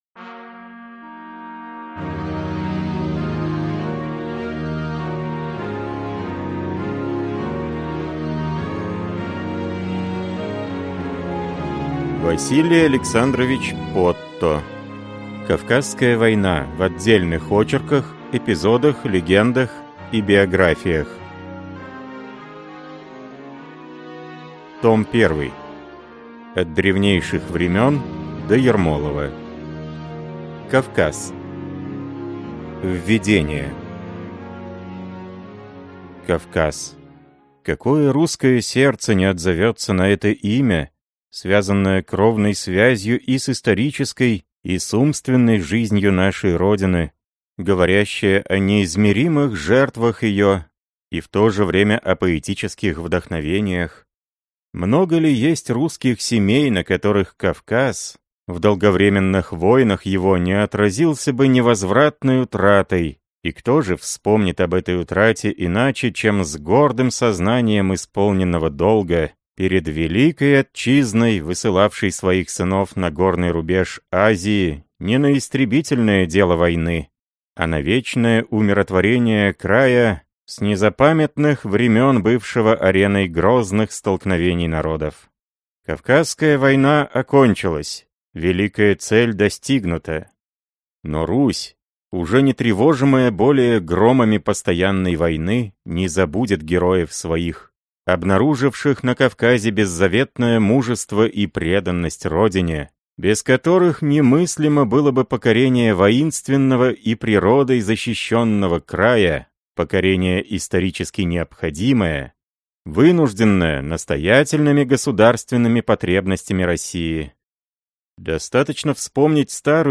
Аудиокнига Кавказская война в отдельных очерках, эпизодах, легендах и биографиях. Том 1. От древнейших времён до Ермолова | Библиотека аудиокниг